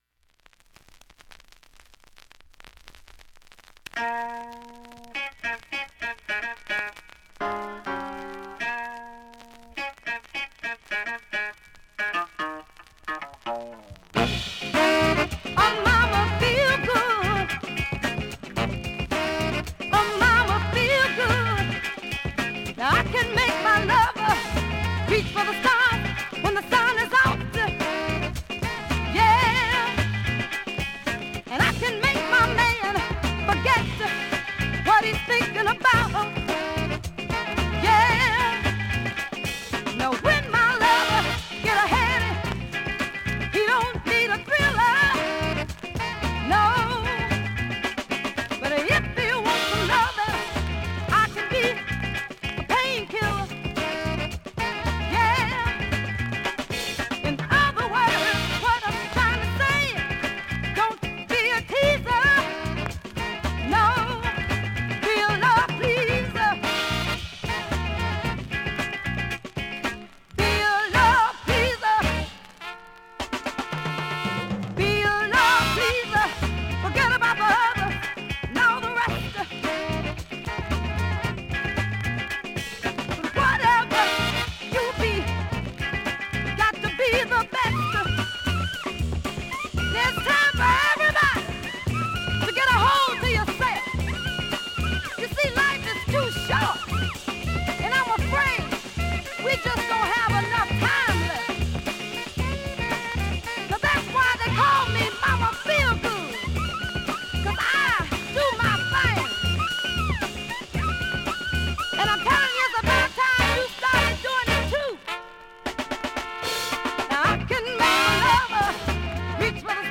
スレ多いですが普通に聴けます